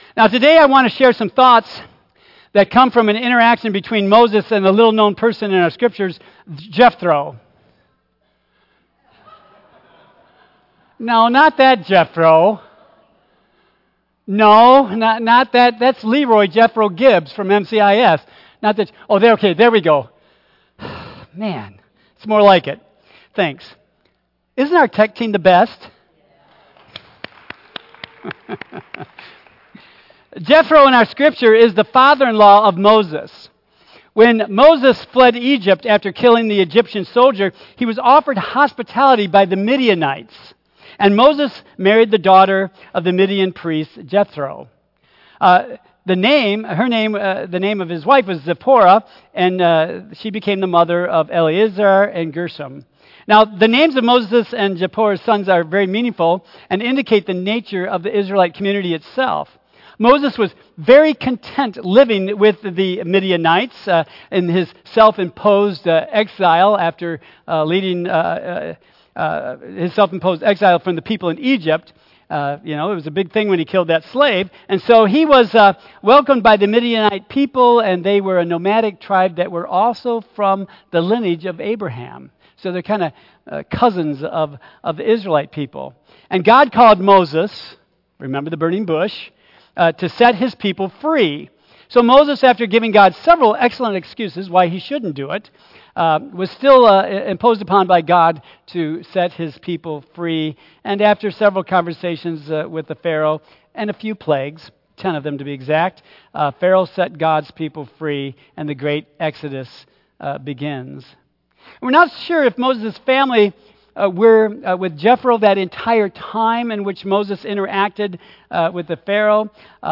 Tagged with Michigan , Sermon , Waterford Central United Methodist Church , Worship Audio (MP3) 8 MB Previous Let Them vs. Let God Next Making Cents of Shrewdness